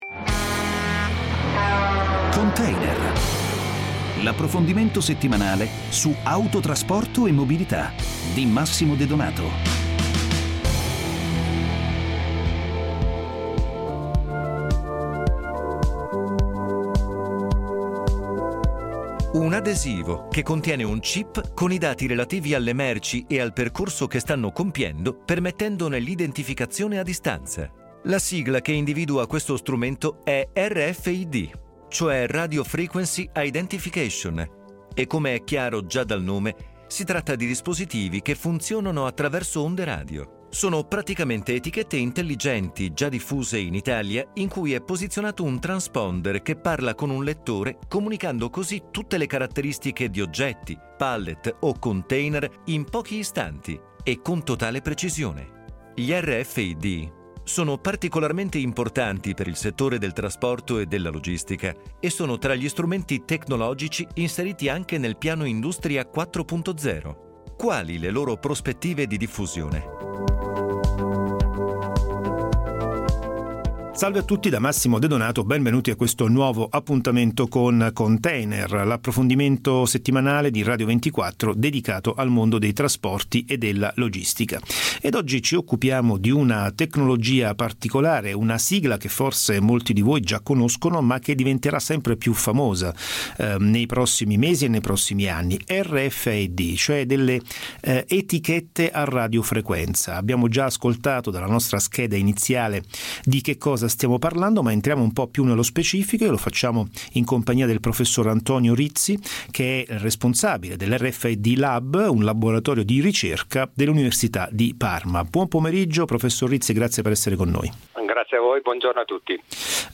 Dalla trasmissione Container su Radio 24 del 31 maggio 2017